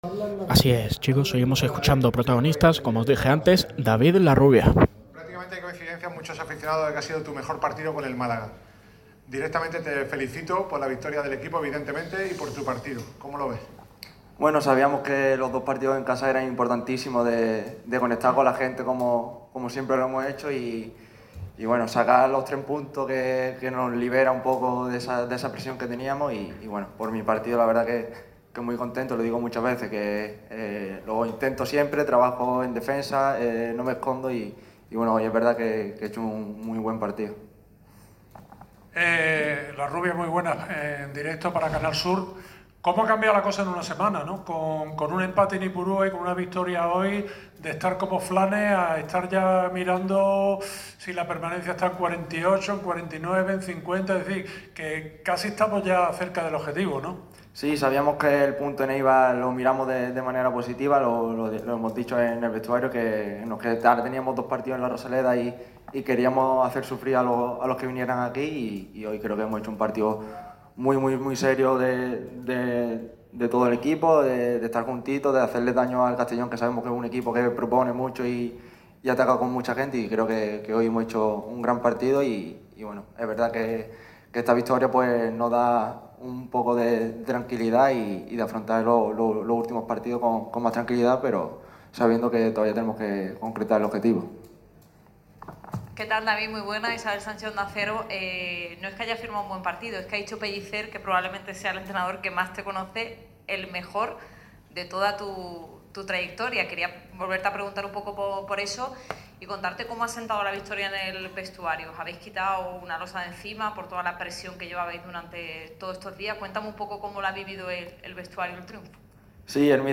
ha comparecido en rueda de prensa tras el triunfo blanquiazul en casa ante el CD Castellón.